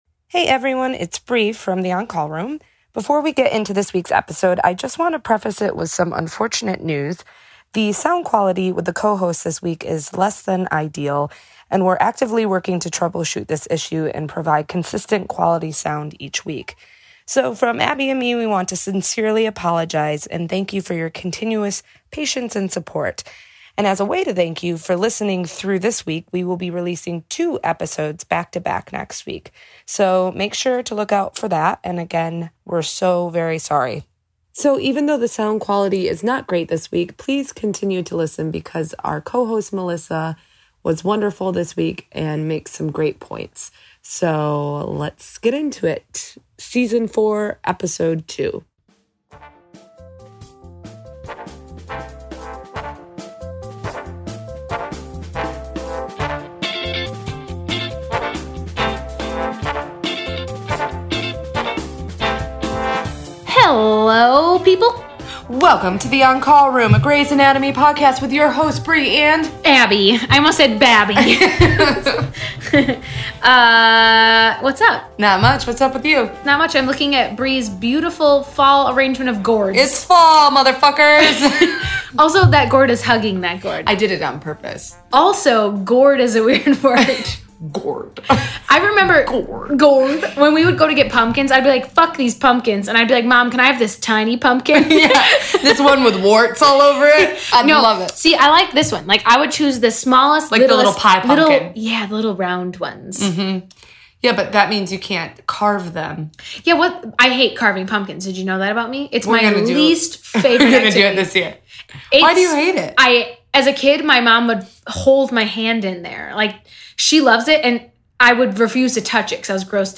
ATTN: We apologize ahead of time for the audio quality in this episode.